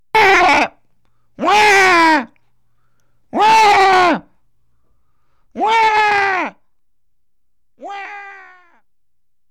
comiccry.mp3